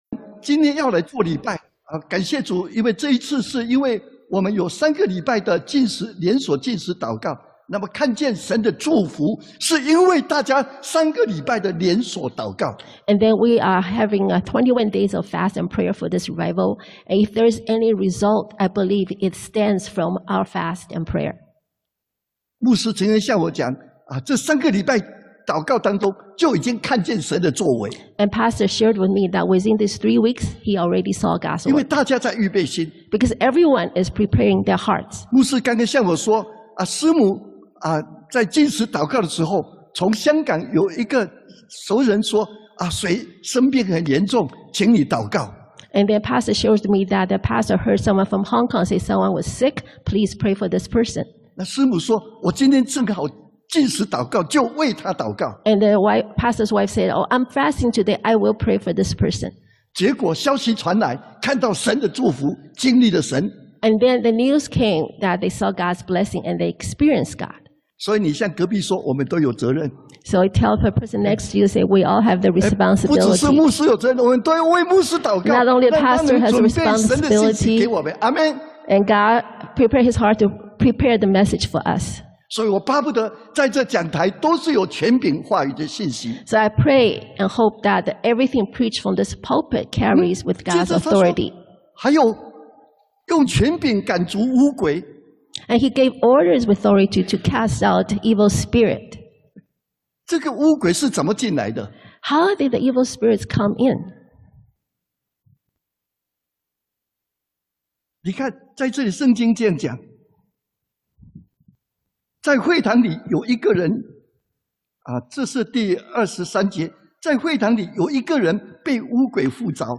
2021-10-10-Revival Worship 主日崇拜
Service Type: Sunday AM